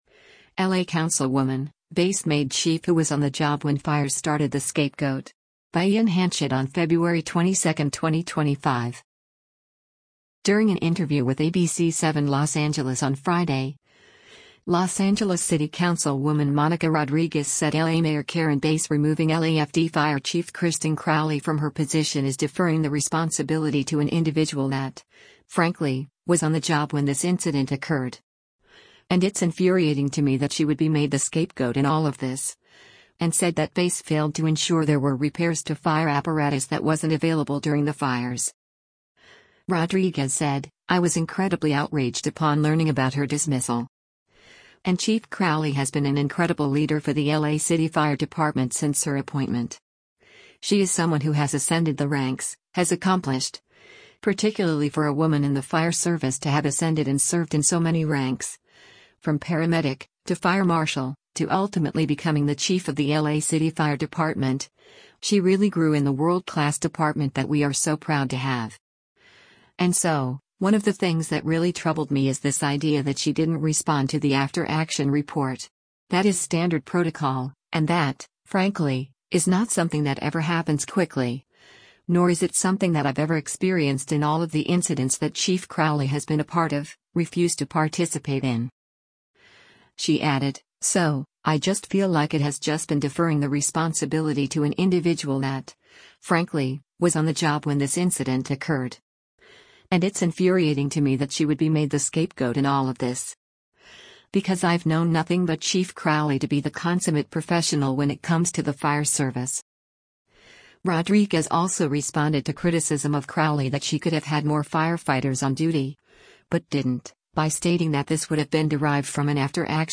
During an interview with ABC7 Los Angeles on Friday, Los Angeles City Councilwoman Monica Rodriguez said L.A. Mayor Karen Bass removing LAFD Fire Chief Kristin Crowley from her position is “deferring the responsibility to an individual that, frankly, was on the job when this incident occurred.